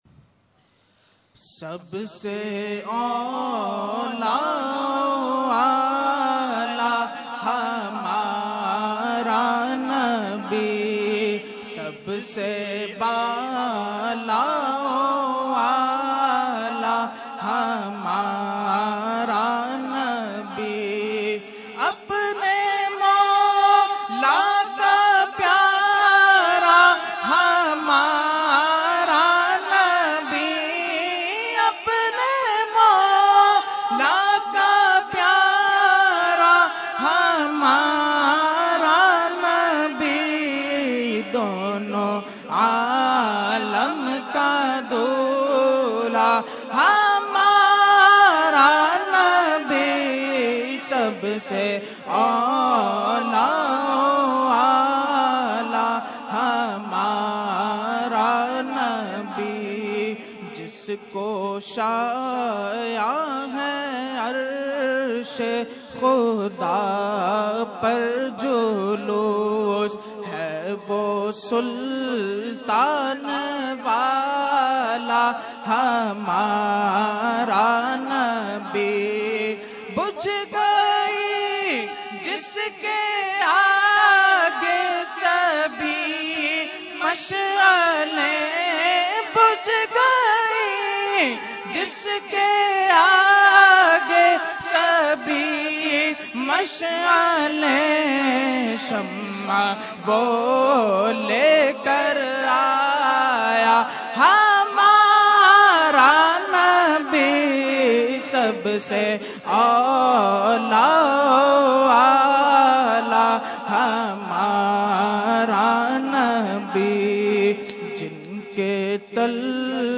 Category : Naat | Language : UrduEvent : Urs Ashraful Mashaikh 2018